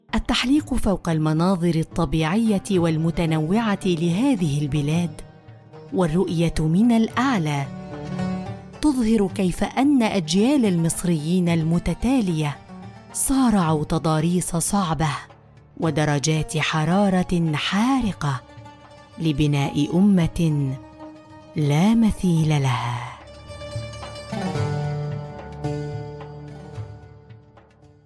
Documentales
Micrófono: Rode NT1-A
Estudio: Estudio casero con tratamiento profesional para una acústica óptima